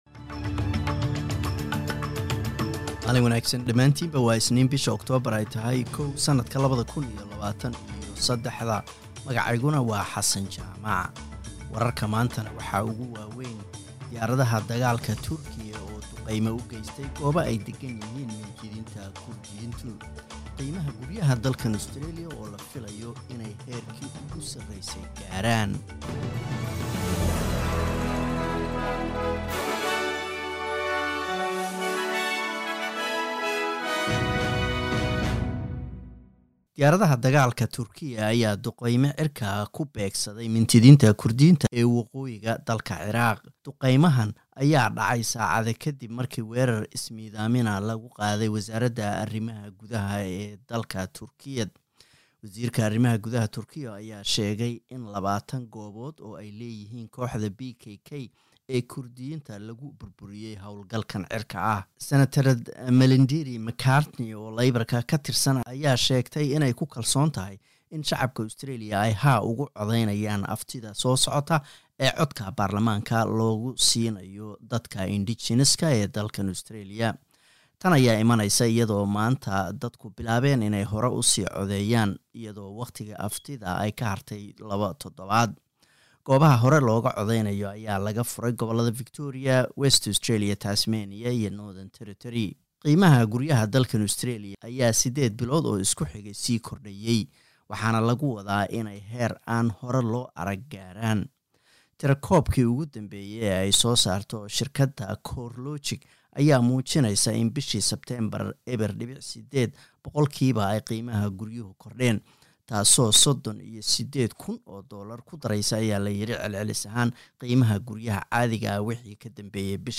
Wararka SBS Somali